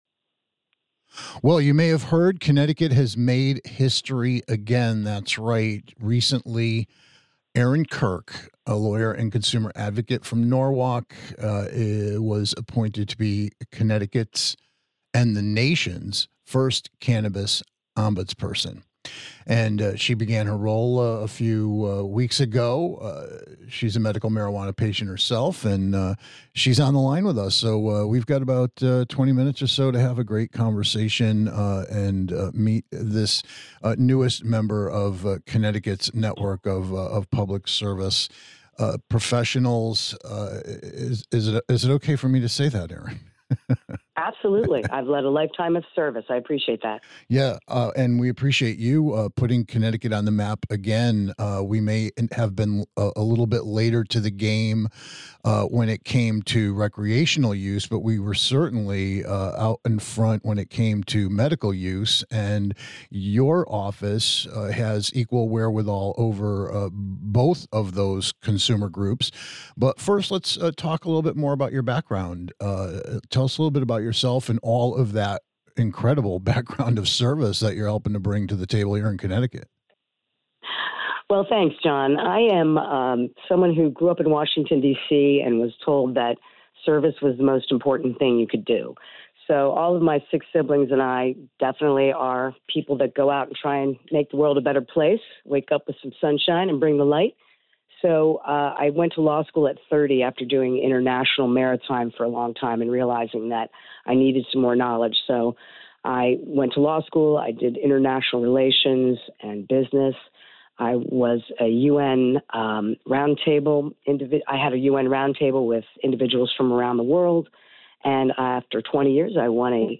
Get ready for a serious chat with Connecticut's - and the nation's - first Cannabis Ombudsperson. Hear all about how this unique government post came to be, and how residents and constituents can engage and access its information resources, support and guidance.